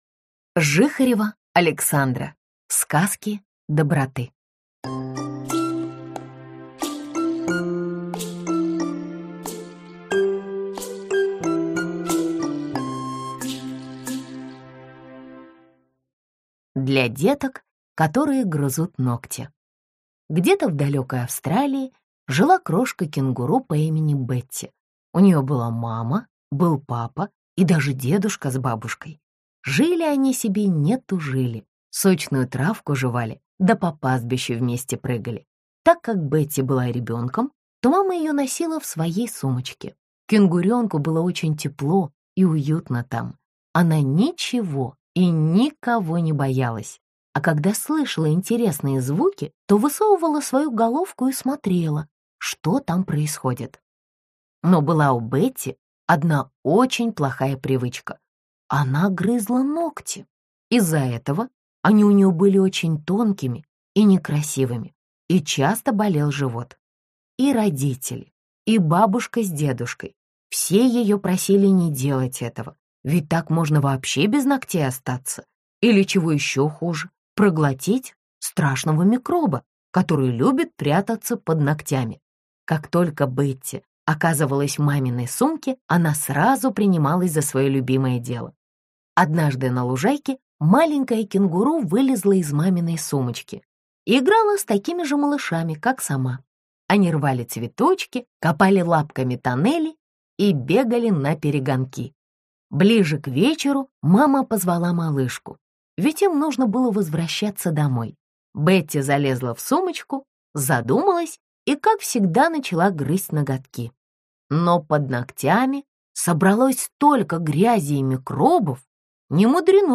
Аудиокнига Сказки доброты | Библиотека аудиокниг